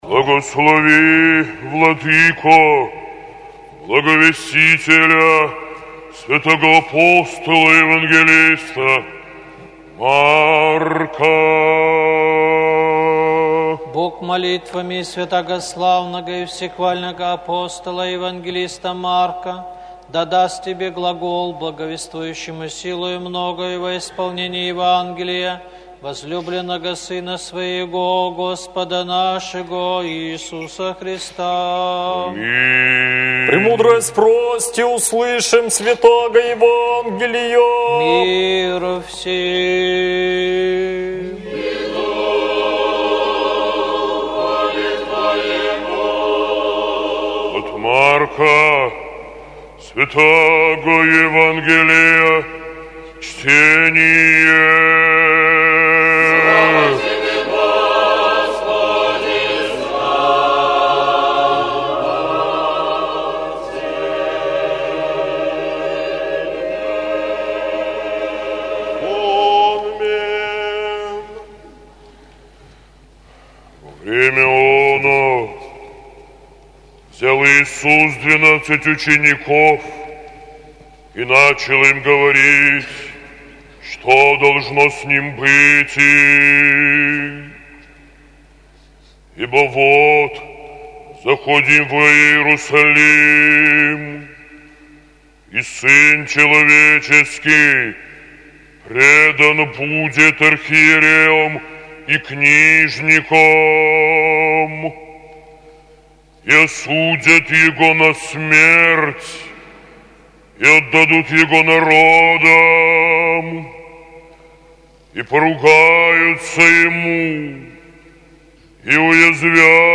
ЕВАНГЕЛЬСКОЕ ЧТЕНИЕ НА литургии